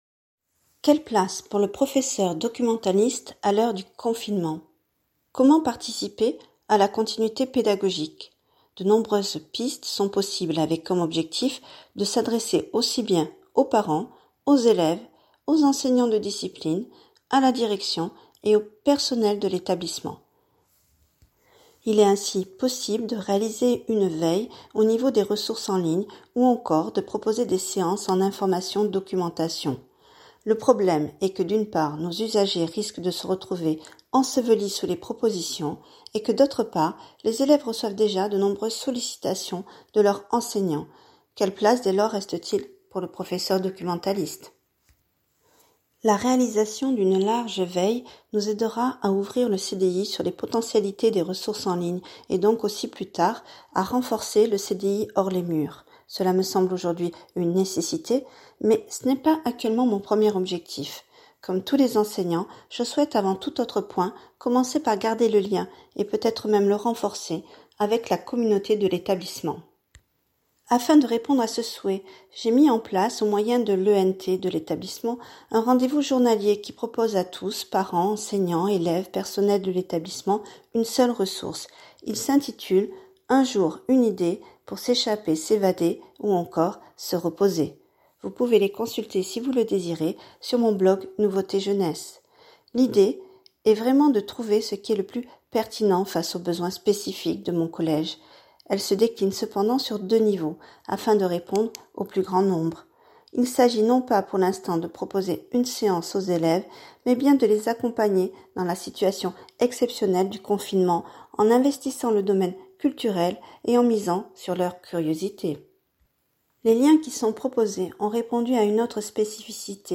Transcription audio de l’article :